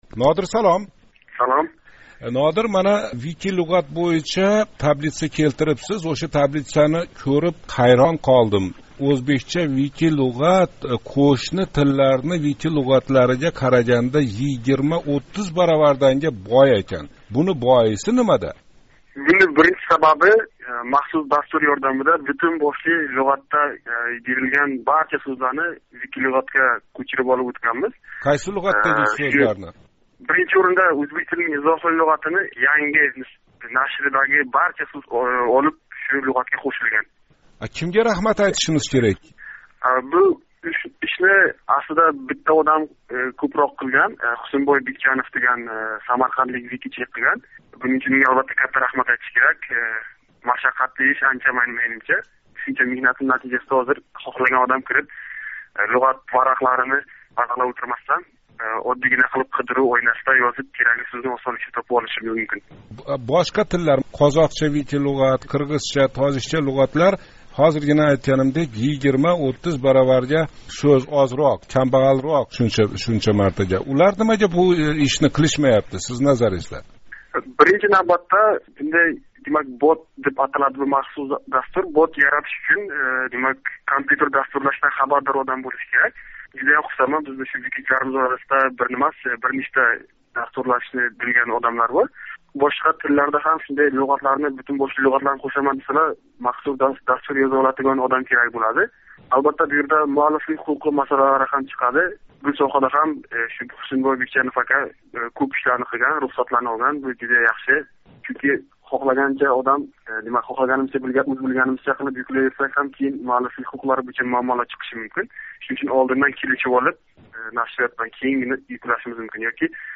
Суҳбатни эшитишга эринганлар қуйидагиларни ўқиб қўйса ҳам бўлаверади :)